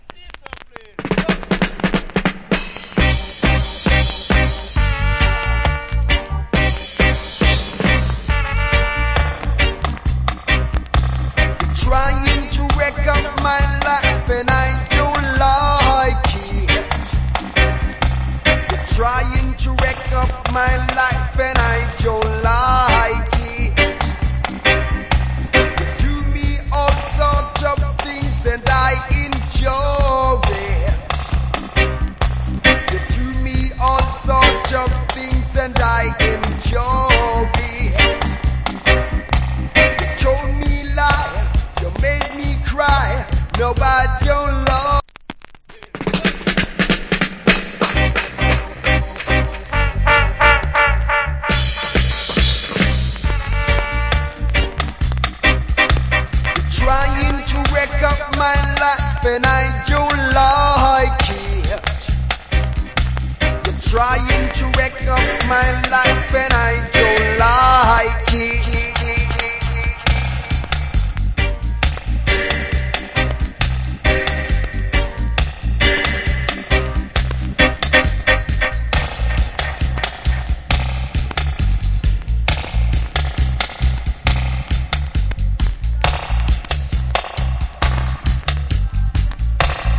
Killer Roots.